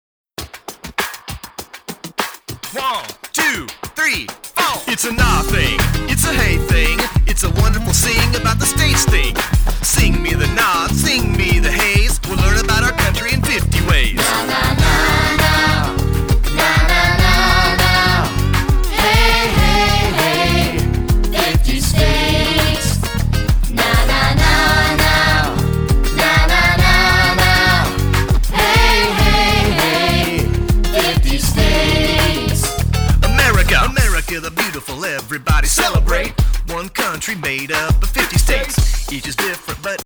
Rap